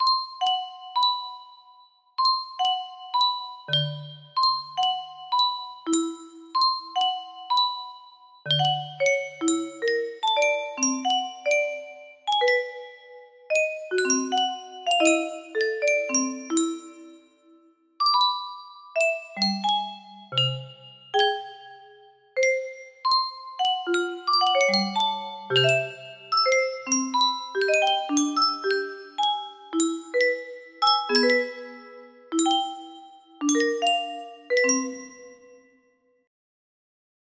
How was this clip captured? It sounds slightly bad.